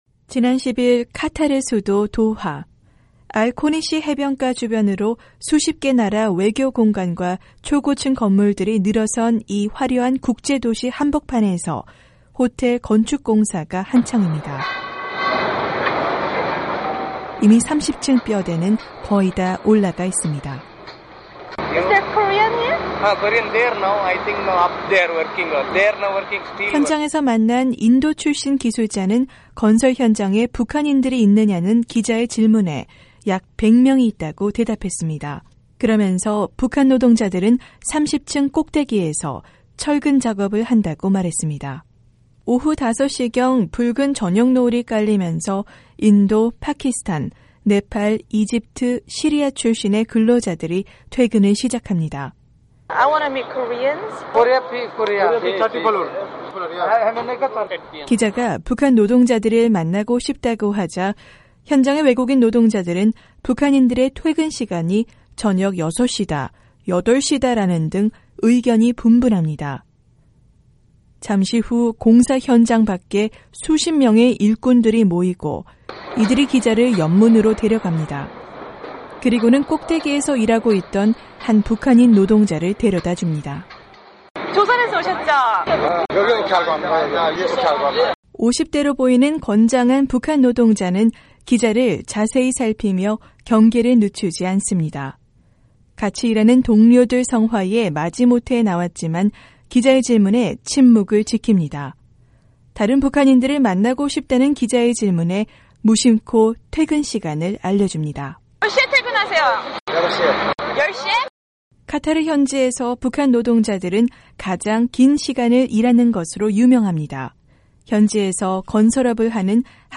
[현지르포] 카타르의 북한 노동자
VOA가 중동 카타르를 방문해 현지에서 일하는 3천여 북한 노동자들의 실태를 취재했다.